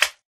Sound / Minecraft / mob / slime2